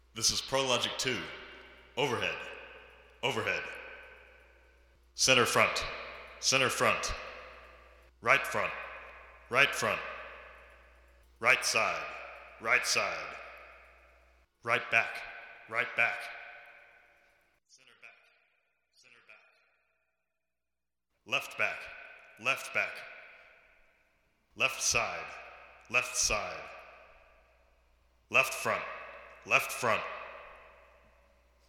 PL-II sample, channel identification
ChID DPL2 Matrix.mp3